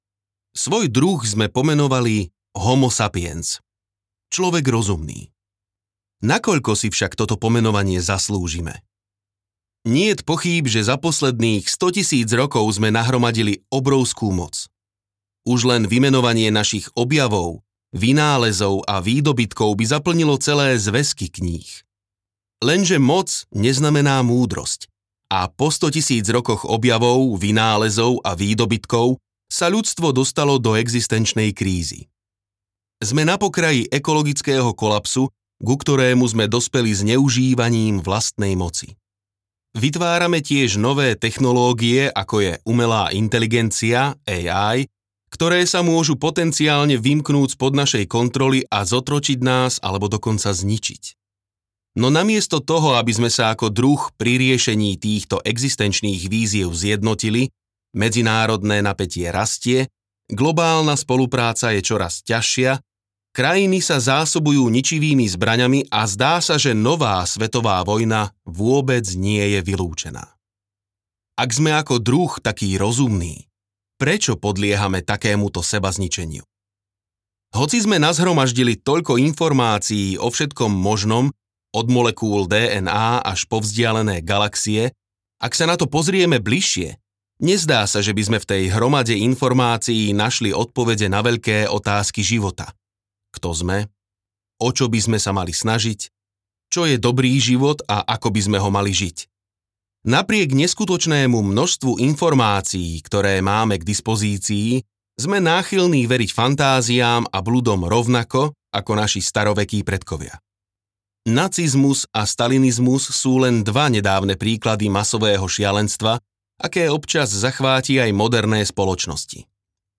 Nexus audiokniha
Ukázka z knihy